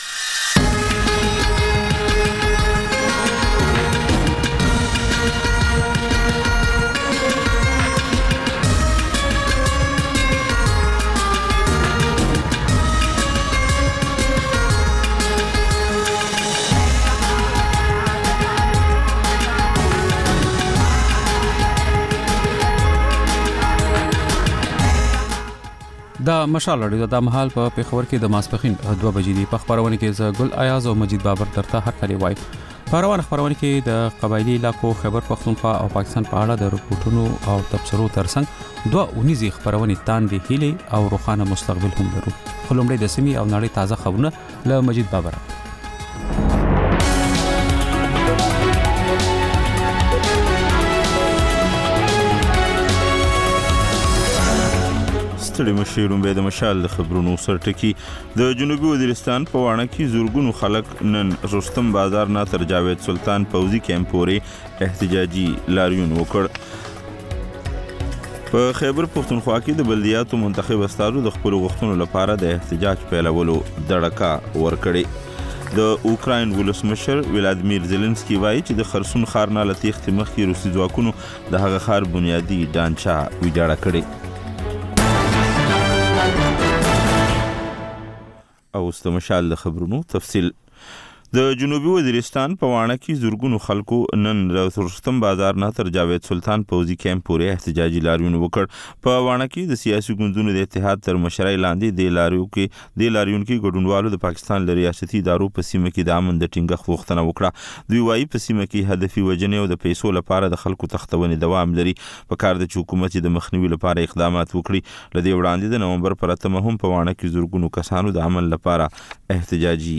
د مشال راډیو دویمه ماسپښینۍ خپرونه. په دې خپرونه کې لومړی خبرونه او بیا ځانګړې خپرونه خپرېږي.